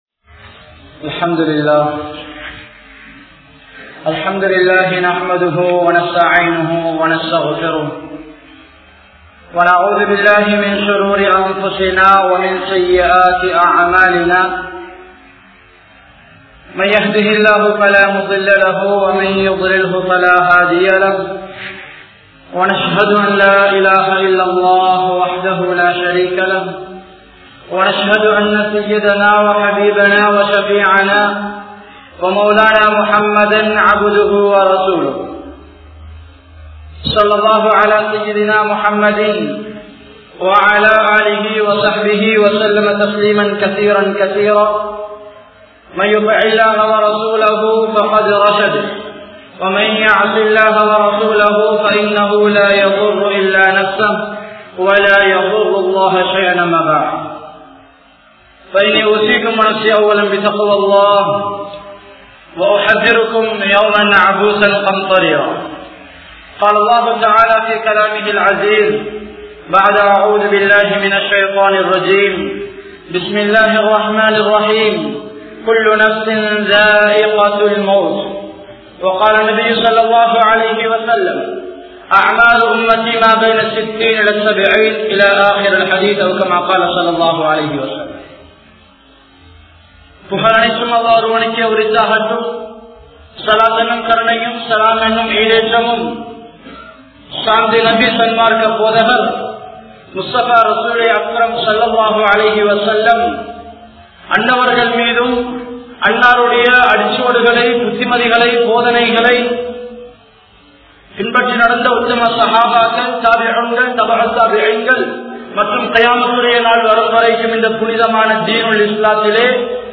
Maranathitku Thayaaraakuvom (மரணத்திற்கு தயாராகுவோம்) | Audio Bayans | All Ceylon Muslim Youth Community | Addalaichenai
Gongawela Jumua Masjidh